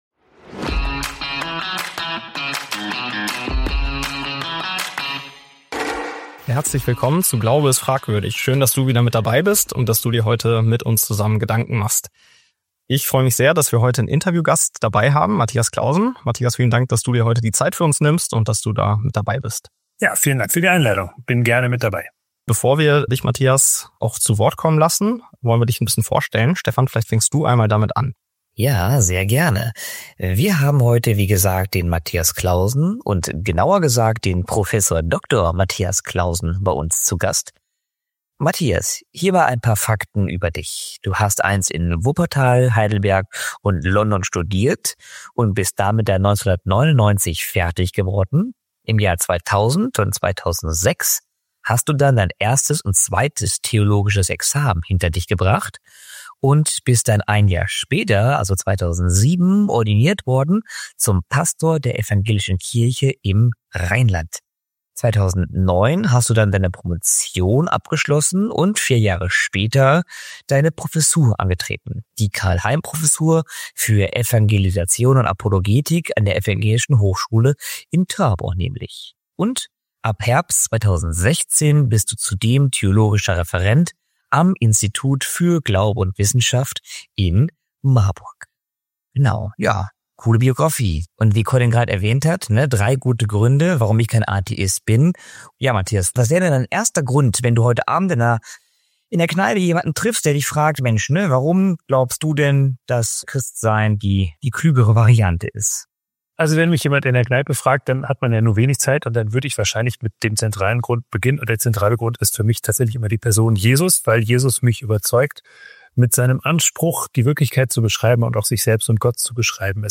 Theologe im Interview